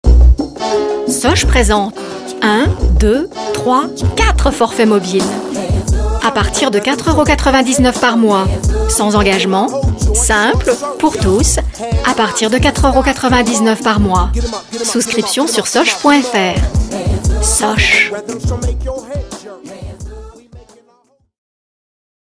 voice over
Comédienne